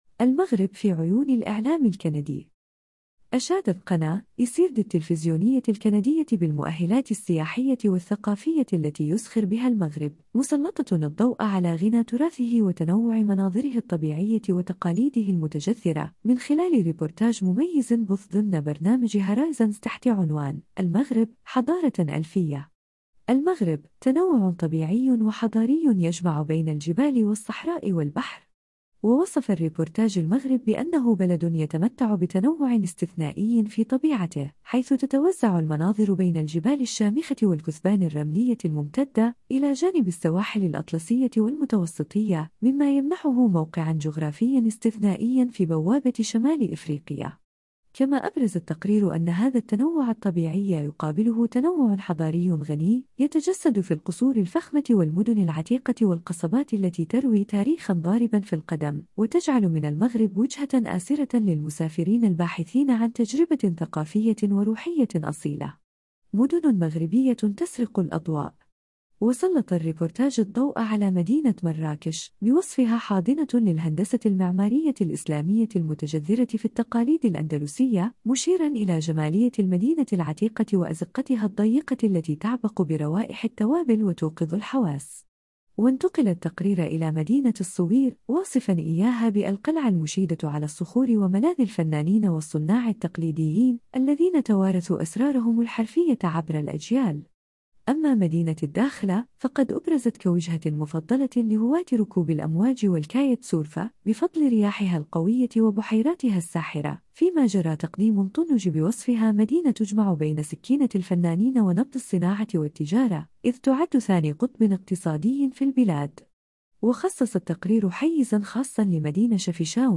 أشادت قناة (ICI RDI) التلفزيونية الكندية بالمؤهلات السياحية والثقافية التي يزخر بها المغرب، مسلطة الضوء على غنى تراثه وتنوع مناظره الطبيعية وتقاليده المتجذرة، من خلال ربورتاج مميز بُث ضمن برنامج Horizons تحت عنوان: “المغرب، حضارة ألفية”.